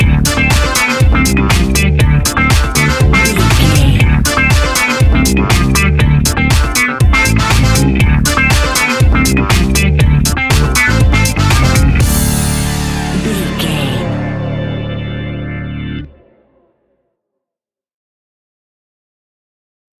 Aeolian/Minor
D
groovy
futuristic
hypnotic
uplifting
bass guitar
electric guitar
drums
synthesiser
funky house
disco house
electro funk
energetic
upbeat
synth leads
Synth Pads
synth bass
drum machines